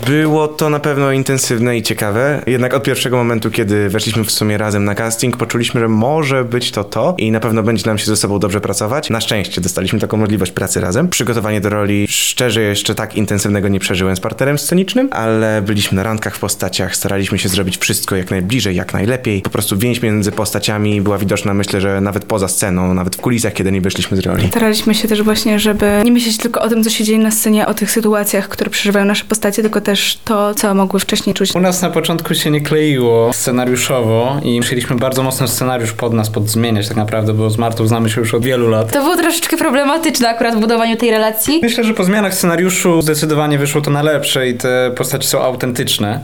aktorzy spektaklu „Wianki”